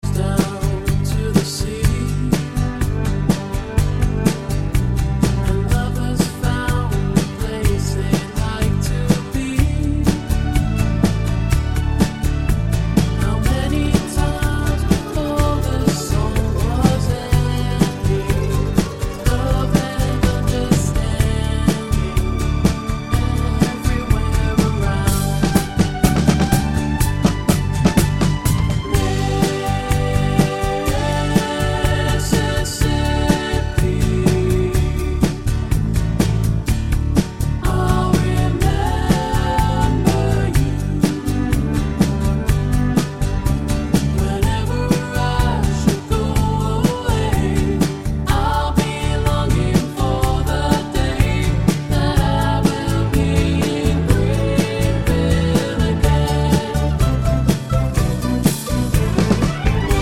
Down 3 Semitones Pop (1970s) 4:34 Buy £1.50